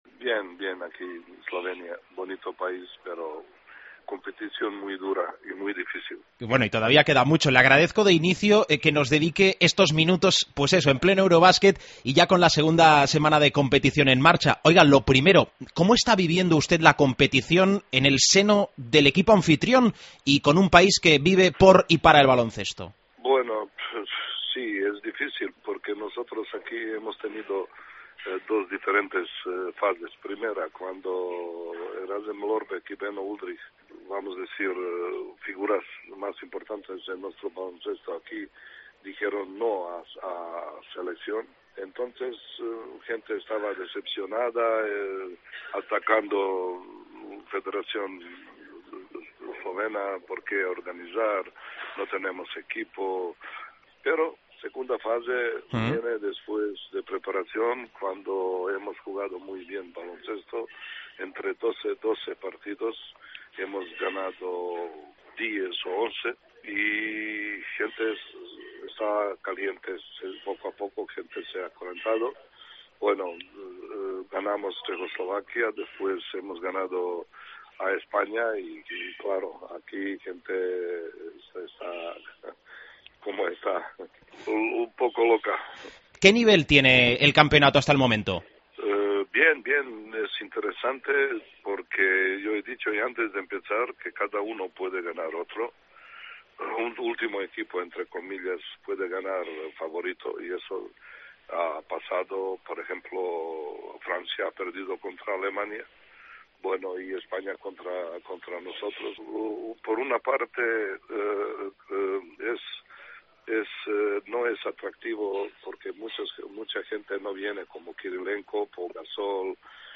Entrevista a Bozidar Maljkovic